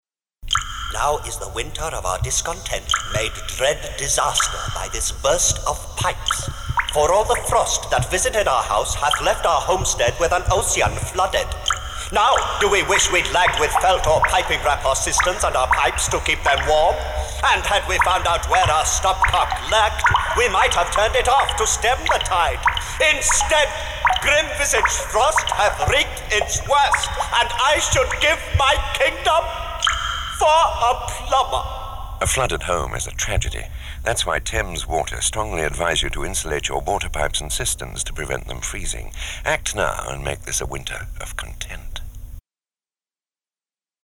Thames Water Radio Ad
Thames_Water_radio.mp3